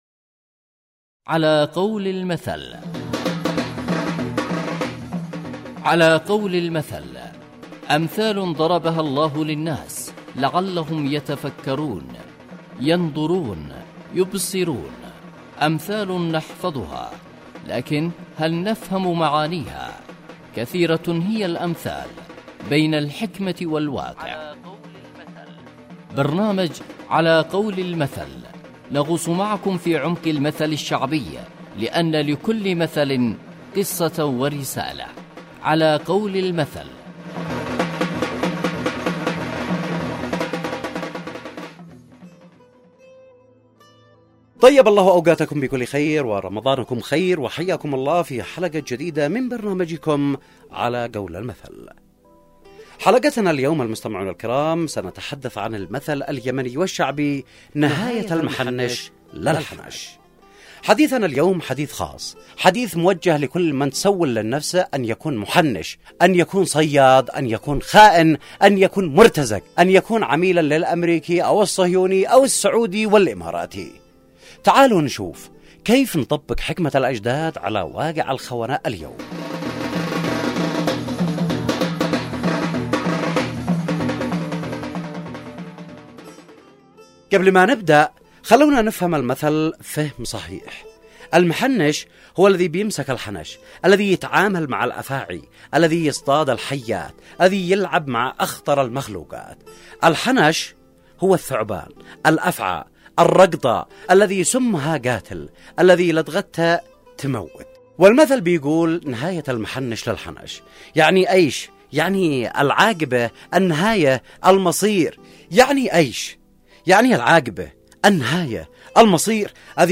برنامج إذاعي يحكي عن معاني الامثال والحكمة منها كالمثل العسكري أو المثل المرتبط بأية قرآنية او المثل الشعبي . ويقدم تفسير للمثل والظروف التي أحاطت بالمثل وواقع المثل في حياتنا اليوم ويستهدف المجتمع.